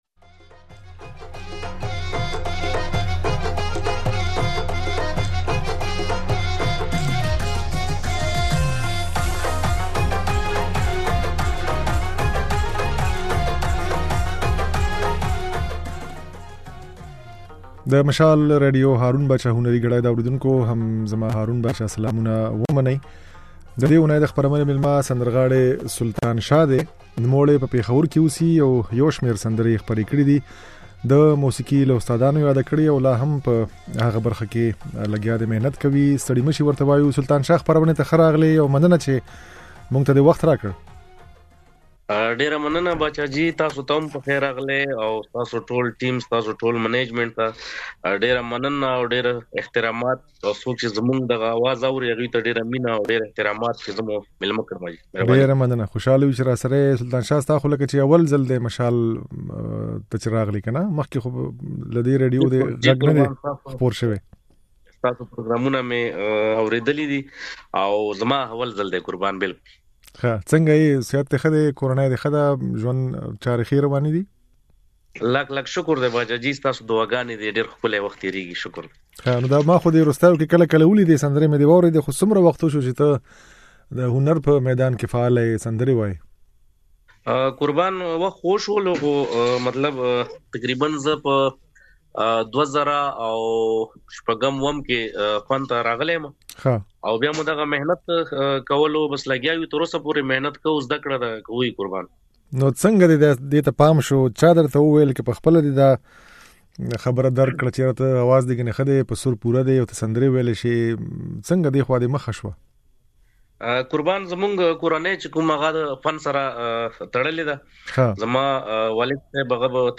او ځينې سندرې يې په خپرونه کې اورېدای شئ